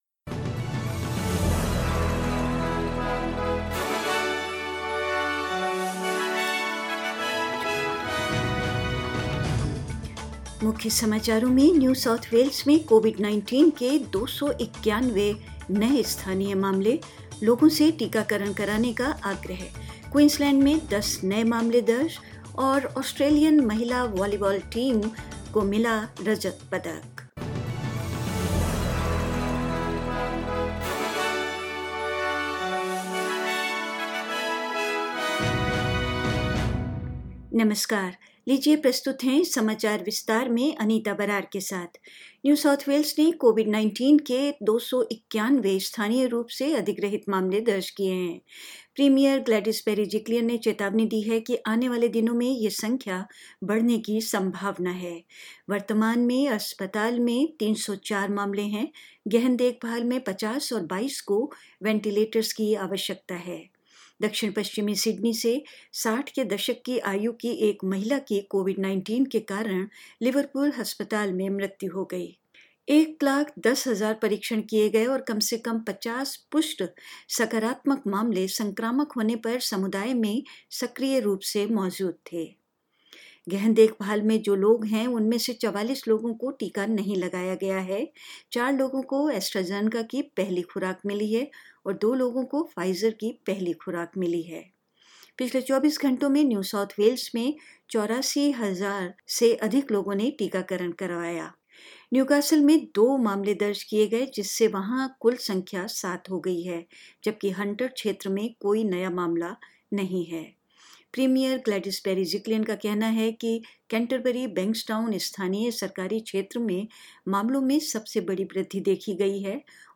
In this latest SBS Hindi News bulletin of Australia and India: New South Wales records 291 new local cases of COVID-19 with authorities urging people to get vaccinated; Queensland records 10 new cases all linked to a known cluster; Tokyo Olympics - the United States proves too good for Australia, taking out gold in the beach volleyball finals; Indian women hockey team loses 3-4 to Great Britain in Bronze medal match and more